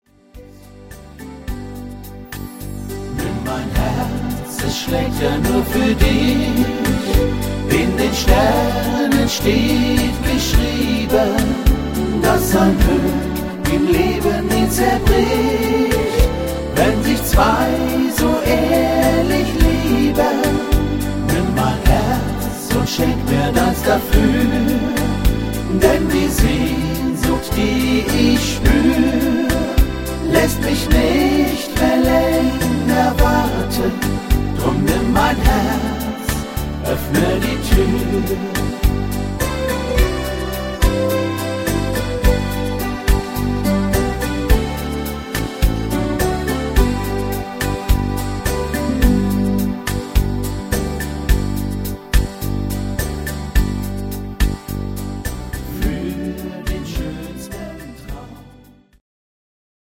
Rhythmus  Beguine
Art  Deutsch, Volkstümlicher Schlager